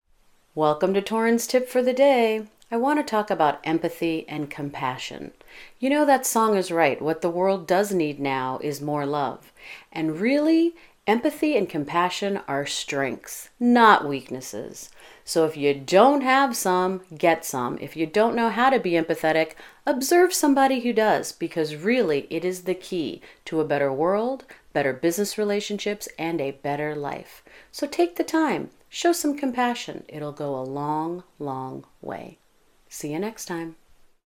Click here to listen to an audio message from this speaker.